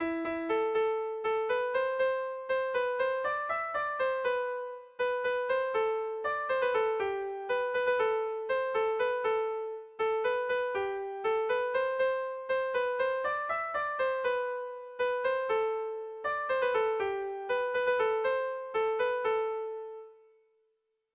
Air de bertsos - Voir fiche   Pour savoir plus sur cette section
Zortzi puntukoa, berdinaren moldekoa
ABA2B